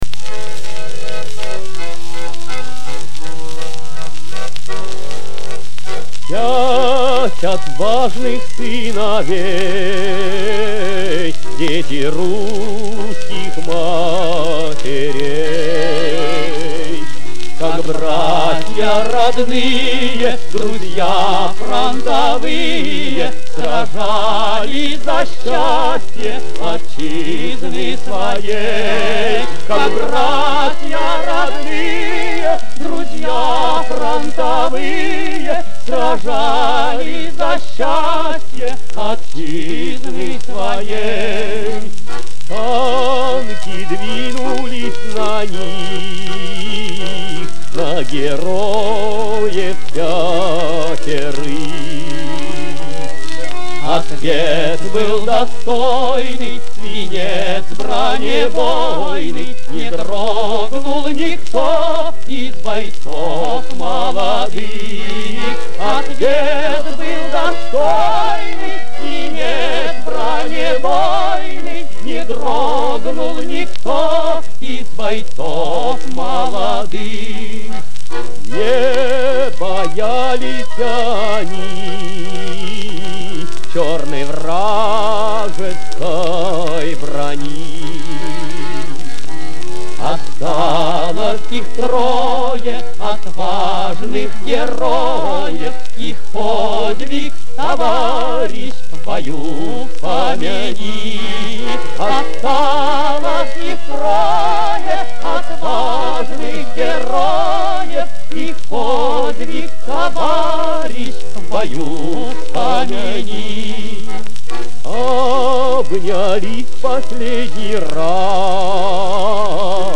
Описание: Улучшение качества